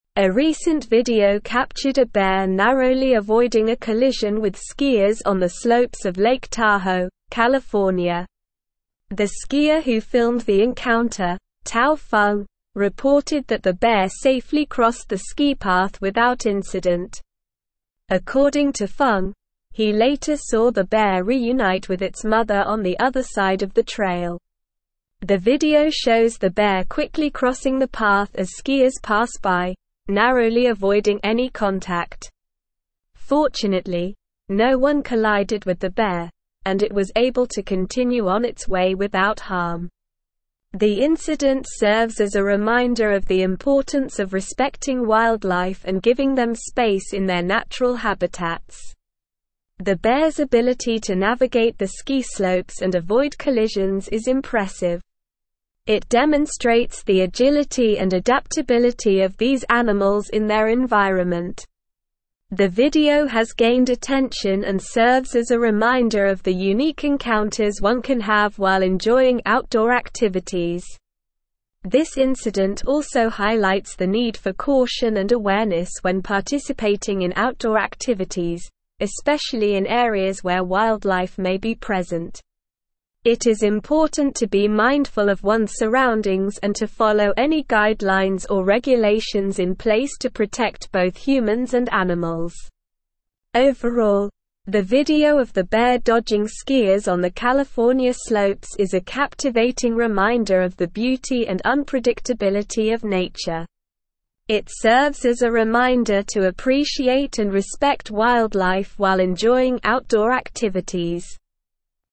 Slow
English-Newsroom-Advanced-SLOW-Reading-Skier-narrowly-avoids-bear-collision-in-Lake-Tahoe.mp3